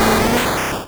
Cri de Coconfort dans Pokémon Rouge et Bleu.